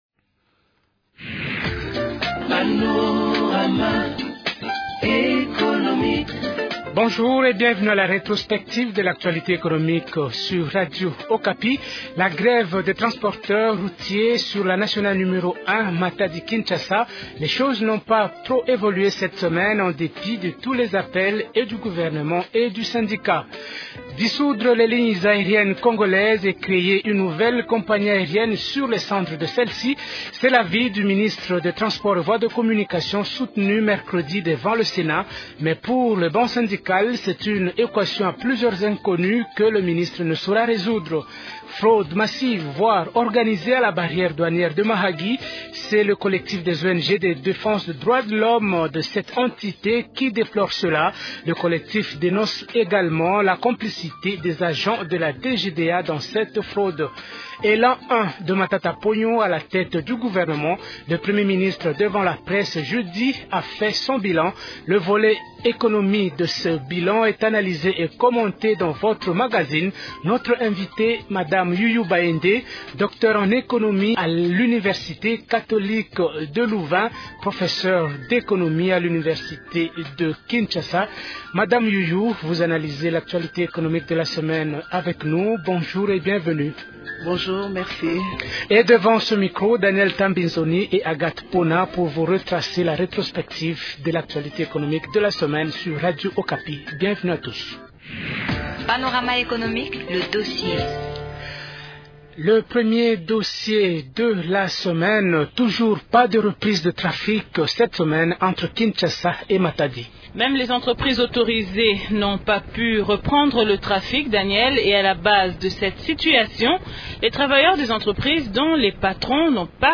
Le volet économique du bilan de l’an un du Premier ministre, Augustin Matata Ponyo, est analysé et commenté dans ce magazine hebdomadaire.